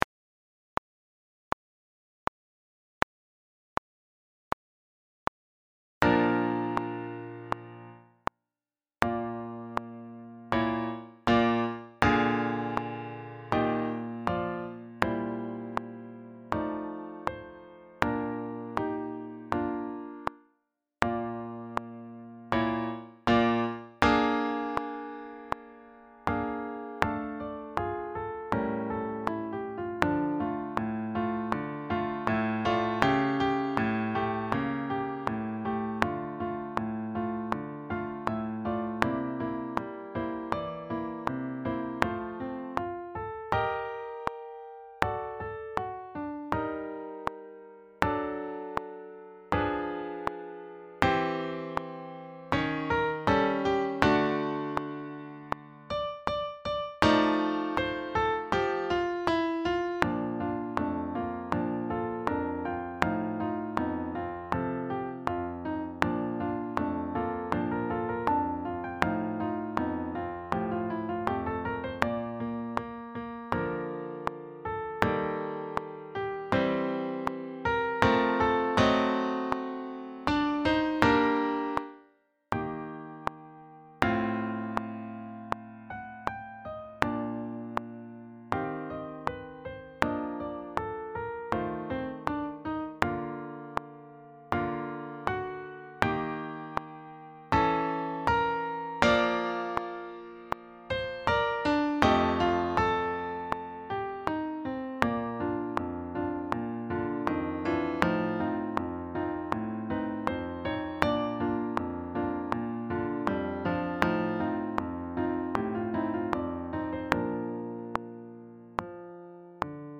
Composed:1857 Grouping:SATB Duration:20:15
175-4-premierquatour-backing-track.mp3